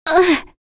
Kirze-Vox_Damage_01.wav